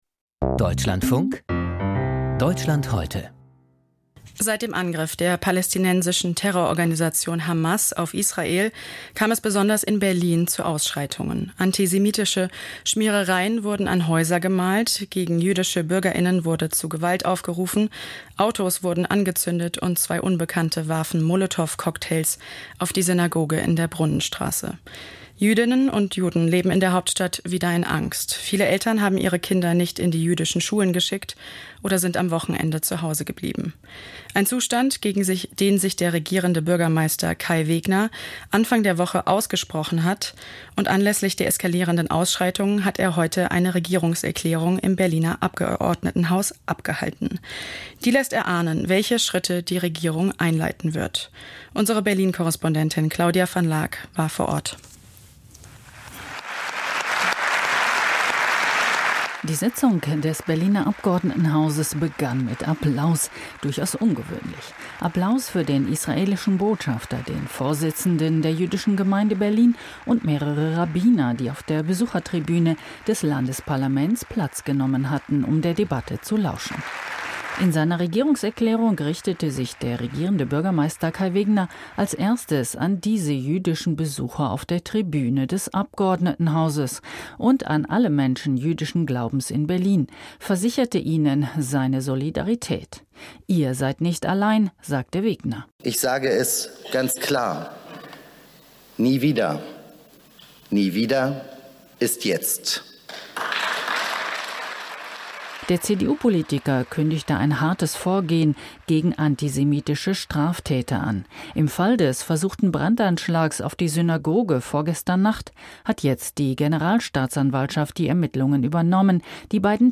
Gegen Antisemitismus - Erklärung des Regierenden Bürgermeisters von Berlin